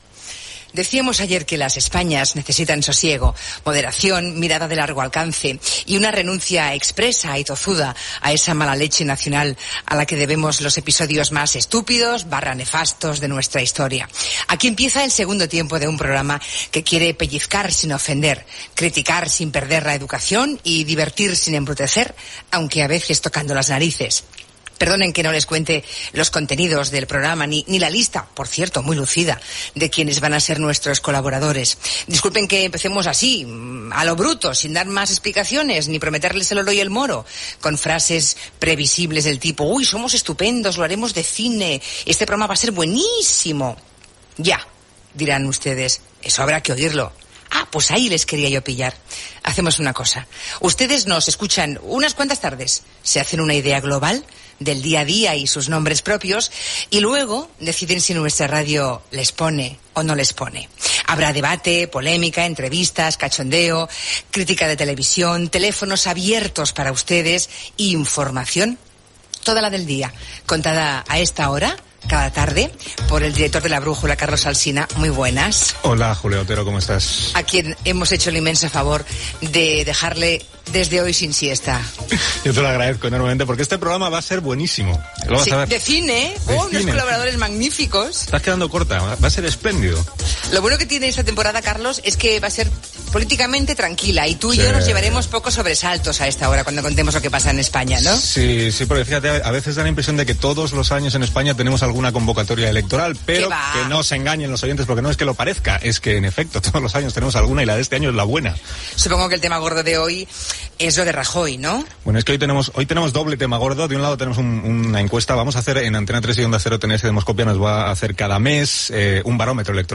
Diàleg entre Julia Otero i Carlos Alsina resumint l'actualitat del dia.
Secció humorística sobre temes d'actualitat.
Entrevista al cantant Joan Manuel Serrat.